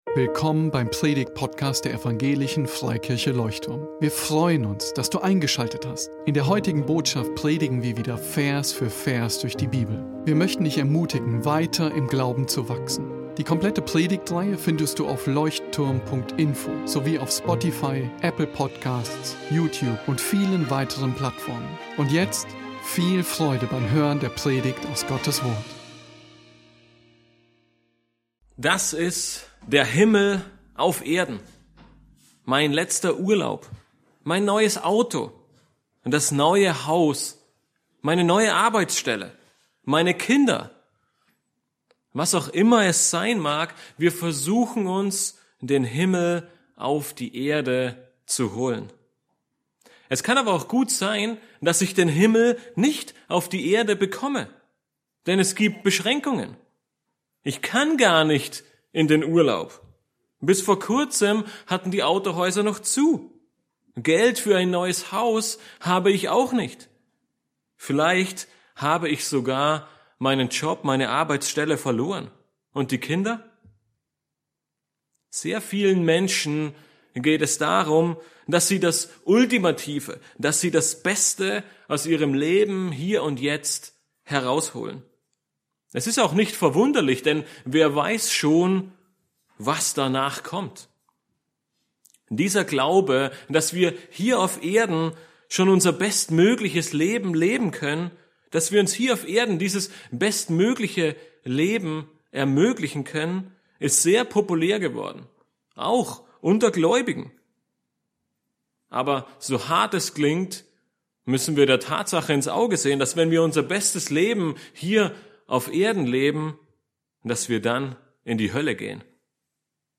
Predigtgliederung Was ist der Himmel eigentlich? (2.Kor 12,2) Wer kommt in den Himmel?